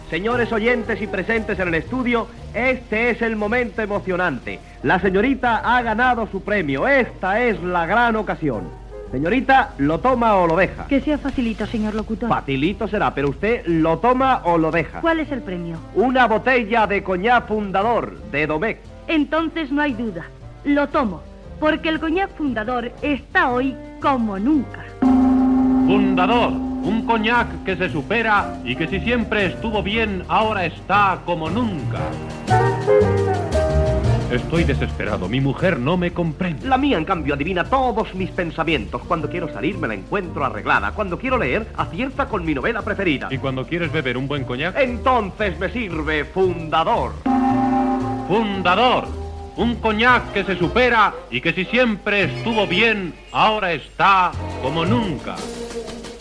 Espai publicitari del conyac Fundador
Entreteniment